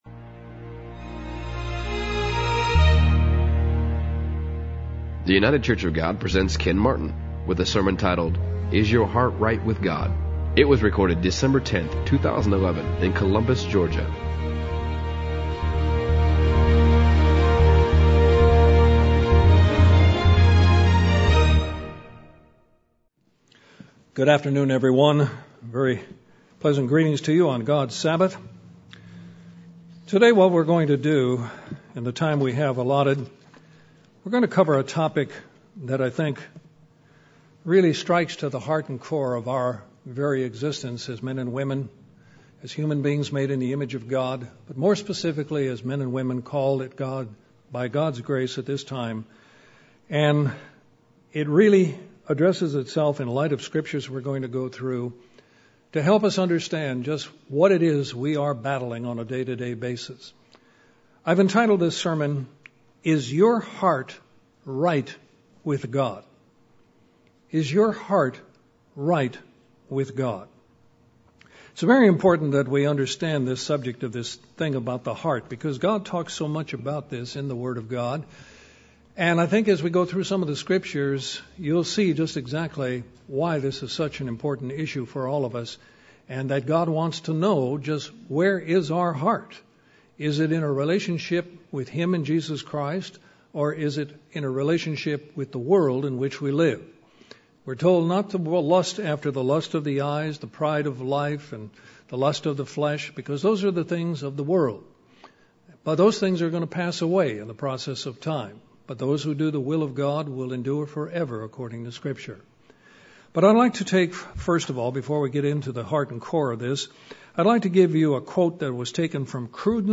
It’s very important that we understand this subject about the heart because God talks so much about this in the Bible. In this sermon, you will see just exactly why this is such an important issue for all of us and that God wants to know just where our heart is.